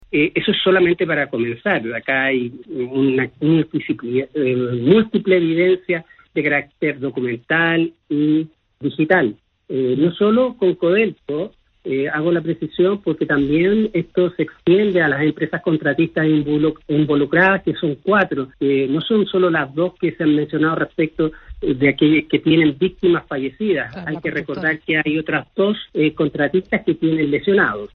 Hemos verificado que no existió ninguna denuncia en su minuto que haya ingresado a la Fiscalía en esa fecha y estamos solicitando al Sernageomin que nos informe si efectivamente hubo alguna investigación, alguna denuncia en ese entonces y ya sea oficiado para que en definitiva se nos haga llegar esos antecedentes», declaró Cubillos en entrevista con el matinal Contigo en la Mañana de Chilevisión.